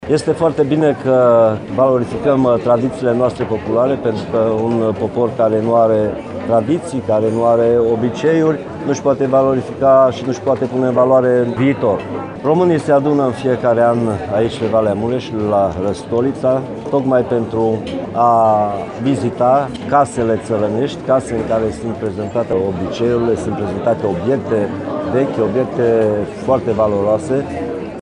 Cea de-a XI-a ediţie a Festivalului Văii Mureşului a avut loc sâmbătă şi duminică la Răstoliţa, pe platoul La Alei.
Prezent la eveniment, ministrul Apărării Naţionale, Mircea Duşa, a apreciat frumuseţea festivalului şi a oamenilor care au participat la acesta.